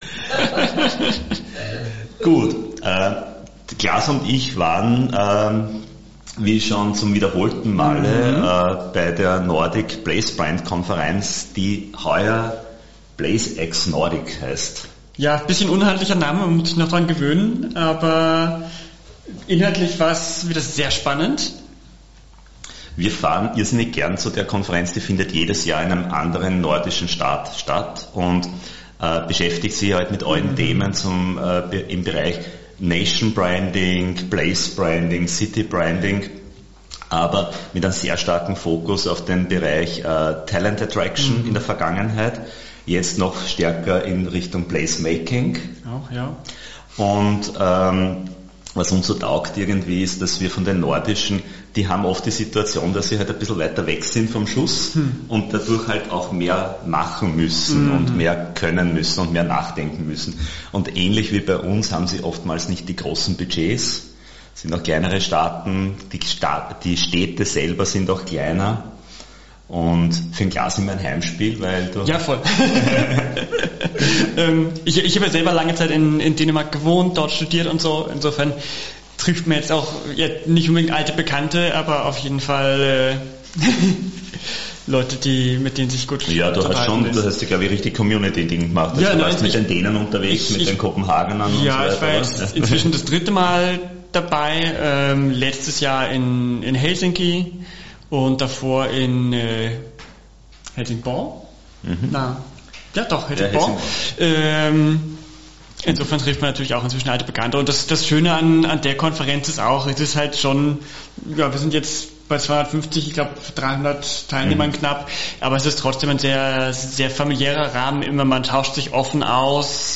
Das ganze Gespräch können Sie hier nachhören, die wesentlichen Punkte haben wir für Sie zusammengefasst.